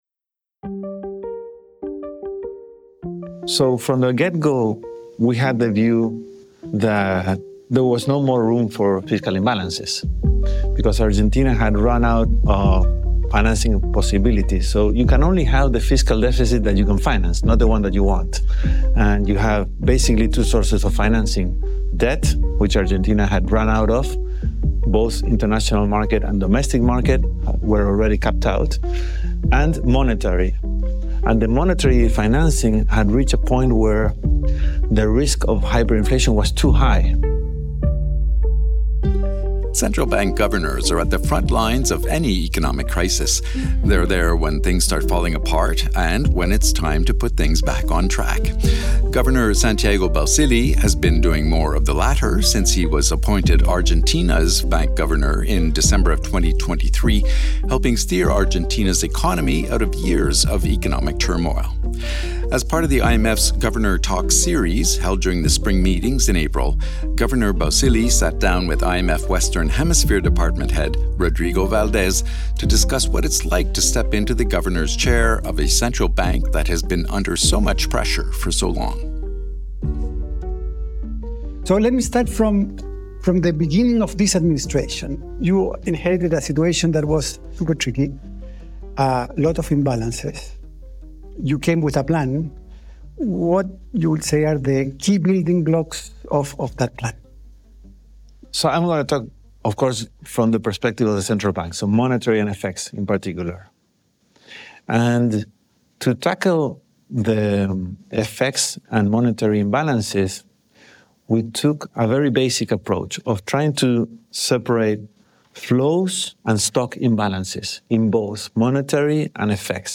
After years of economic turmoil, Argentina’s central bank chief has doubled down on efforts to restore confidence in the Argentine peso and normalize its economy. In this podcast, Governor Santiago Bausili and IMF Western Hemisphere Department head, Rodrigo Valdés discuss the challenging process of stabilizing Argentina’s bi-monetary economy. The conversation occurred in the Governor Talks series held during the IMF-World Bank Spring Meetings.